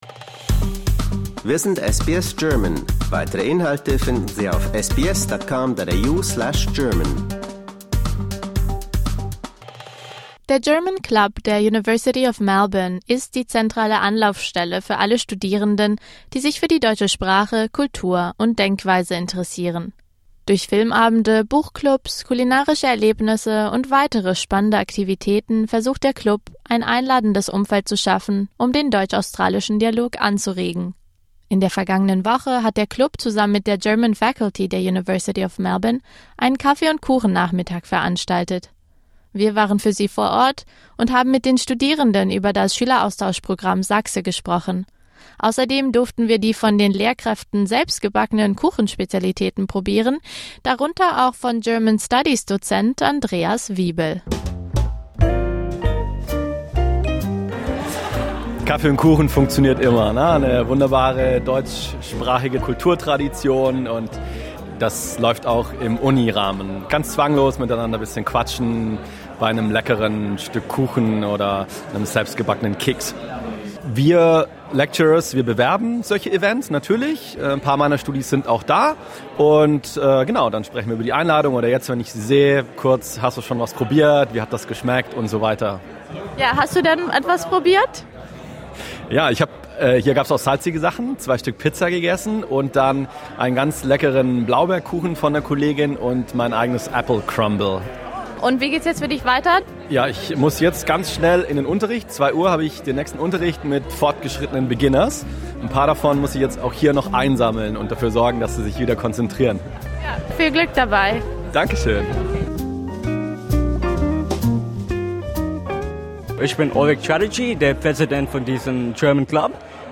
Last week, together with the German Faculty, the Club hosted a coffee and cake event on the campus of the university. We were there to talk to students and teachers about the SAGSE student exchange program and to try the homemade German cake specialties.